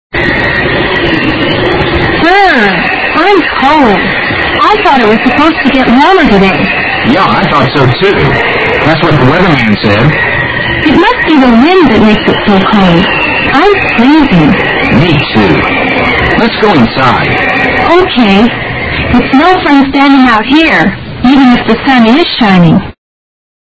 英语对话听力mp3下载Listen 25:FREEZING COLD OUTSIDE
(Wind noises)